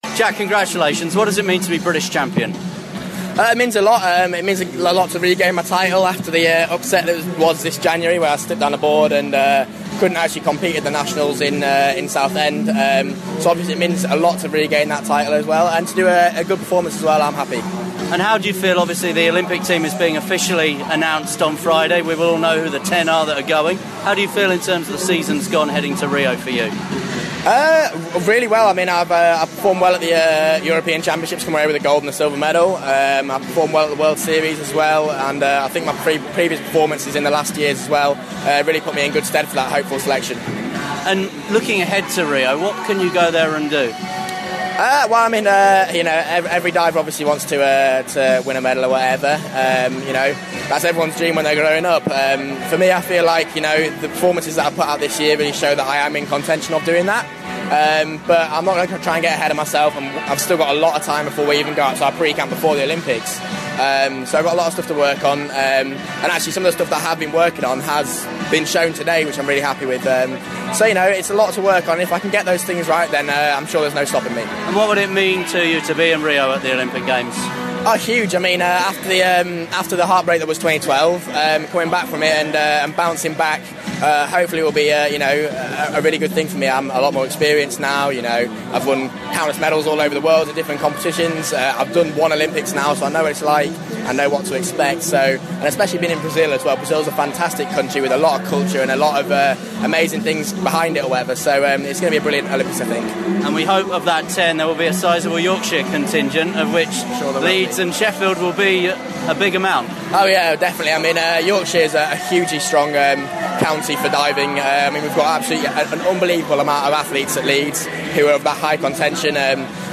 Jack Laugher at the British Diving Championships in Sheffield
at the British Diving Championships in Sheffield.